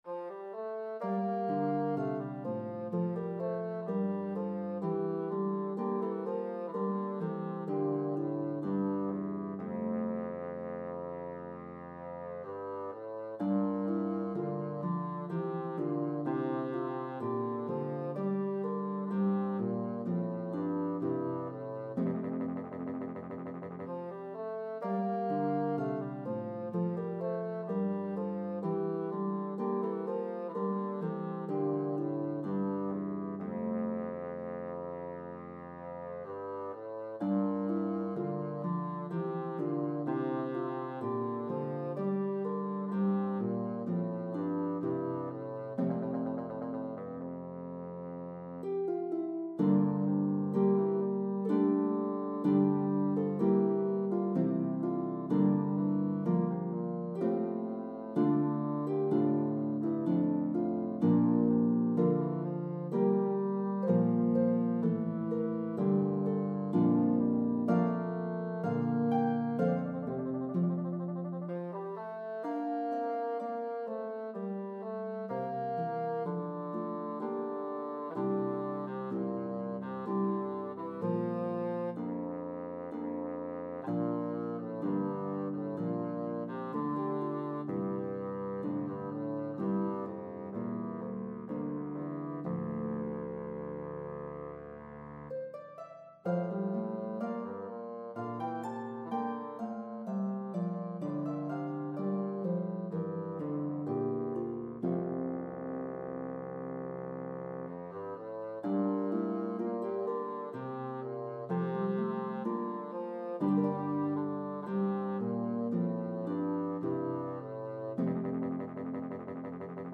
Italian Baroque style
lovely slow air